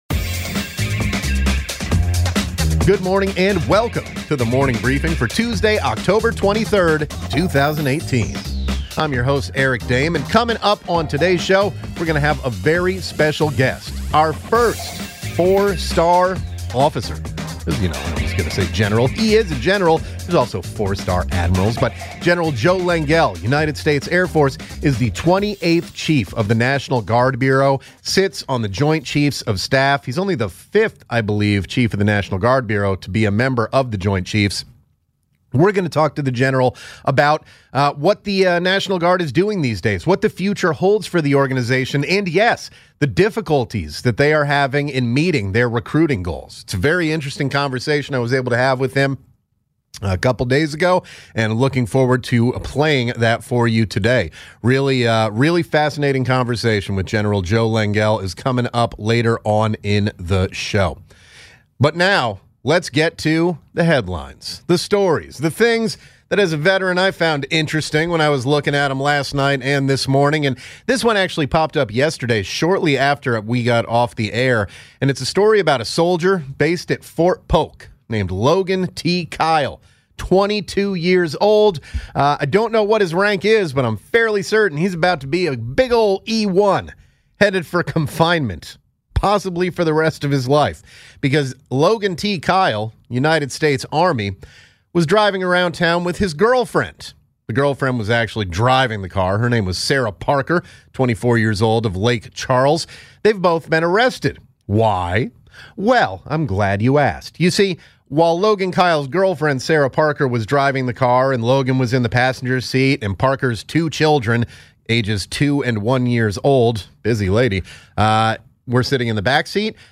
Then General Joseph Lengyel, Chief of National Guard Bureau joins the show for an in-depth interview.